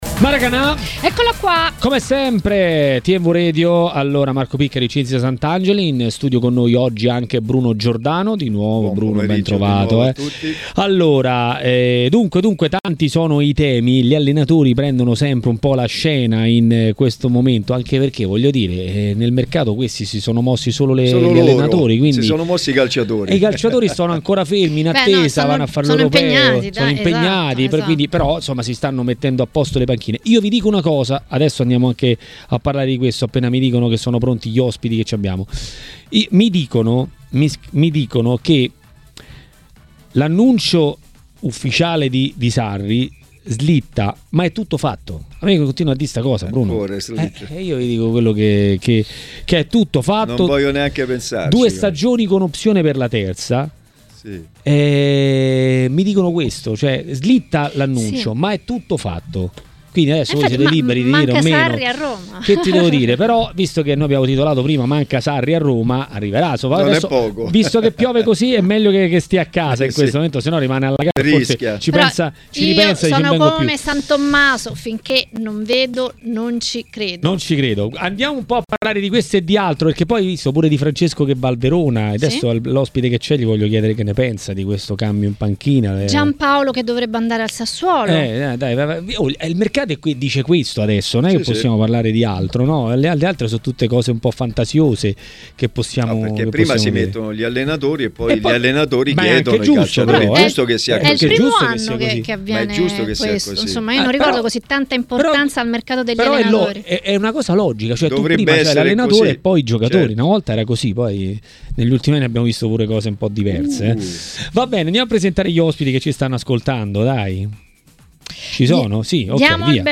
L'ex calciatore e tecnico Alessio Tacchinardi a Maracanà, nel pomeriggio di TMW Radio, ha parlato dei temi di giornata.